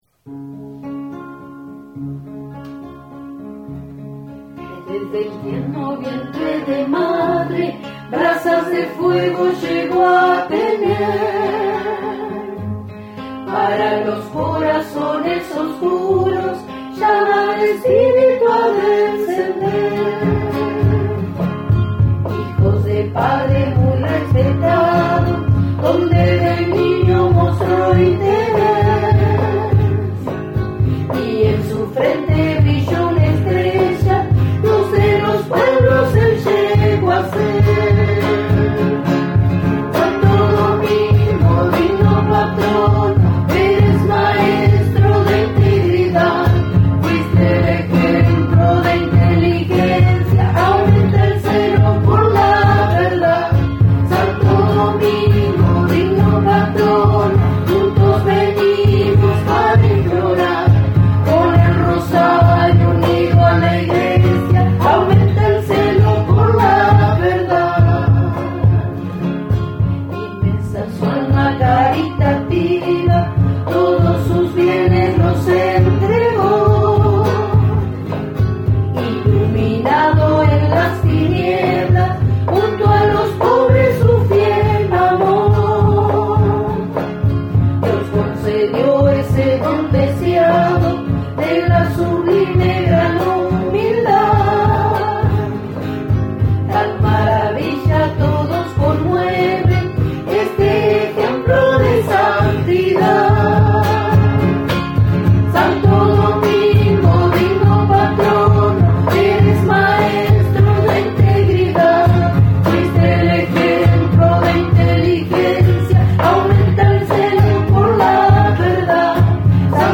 himno-a-Santo-Domingo.mp3